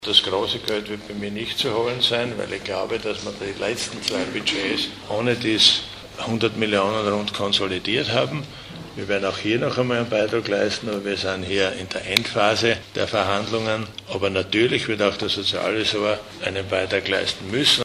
Nachfolgend finden Sie O-Töne aus der heutigen Pressekonferenz:
Antwort LH-Stv. Siegfried Schrittwieser